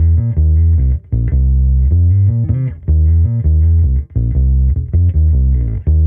Index of /musicradar/sampled-funk-soul-samples/79bpm/Bass
SSF_PBassProc1_79C.wav